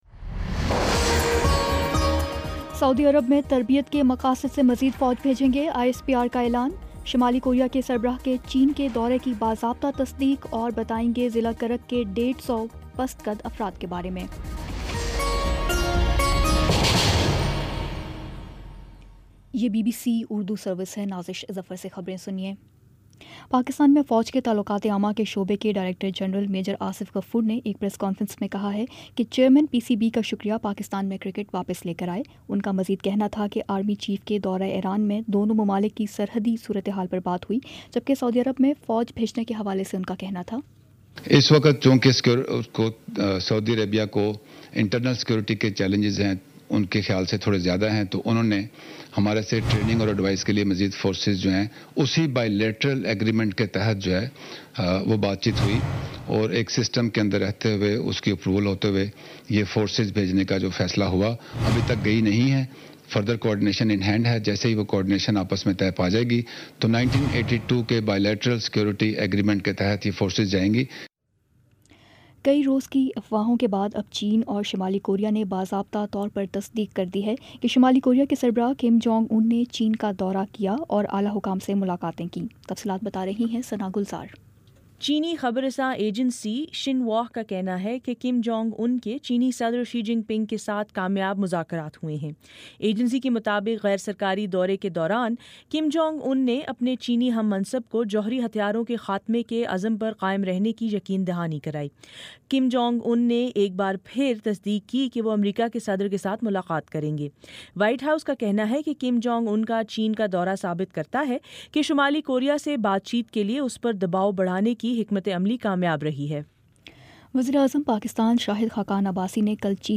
مارچ 28 : شام پانچ بجے کا نیوز بُلیٹن